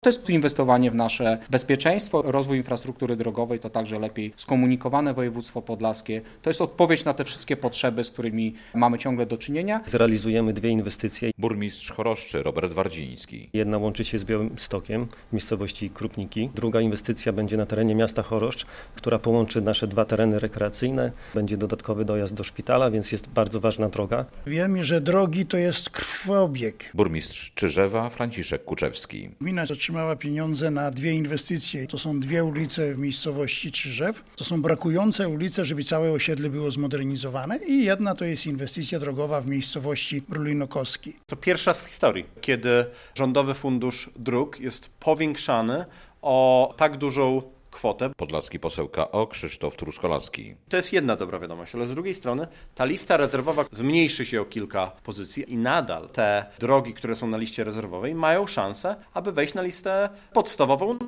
Dodatkowe pieniądze na drogi w regionie - relacja
To dobra informacja dla mieszkańców. To jest inwestowanie w nasze bezpieczeństwo, rozwój infrastruktury drogowej to też lepiej skomunikowane województwo - mówi wojewoda podlaski Jacek Brzozowski.